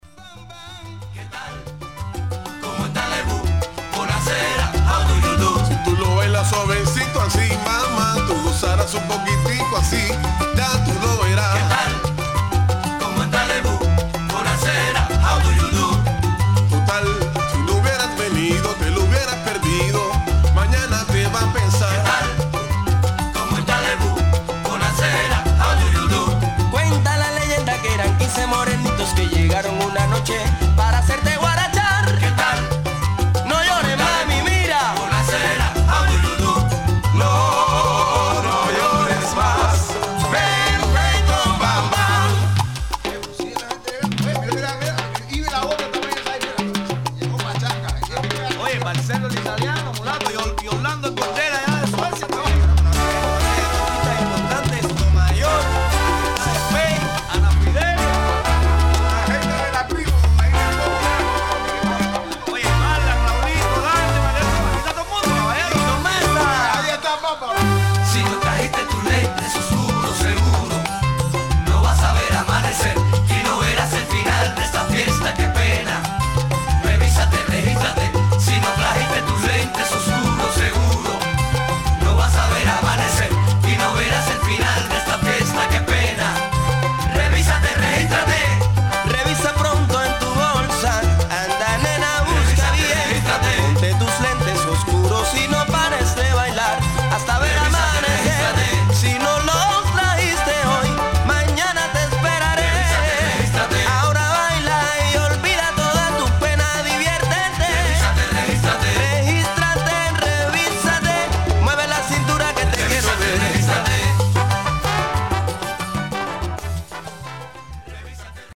90'S Cuban Salsa のコンピレーション